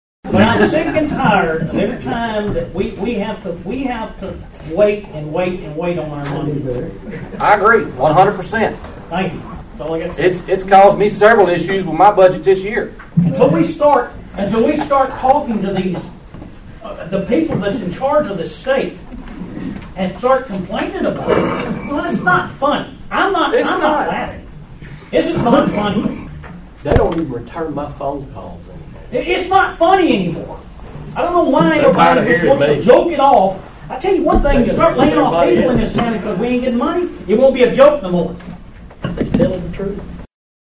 The lack of timely funding to the Obion County Recycling Center drew sharp criticism at Friday’s County Commission meeting.
Commissioner Jowers continued to talk about the lack of timely payments from the state.(AUDIO)